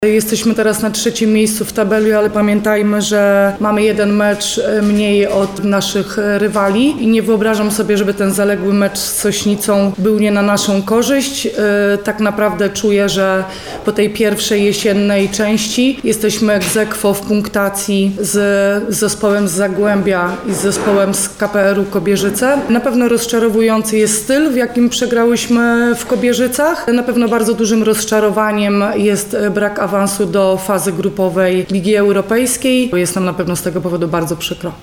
-mówiła podczas specjalnie zwołanej konferencji prasowej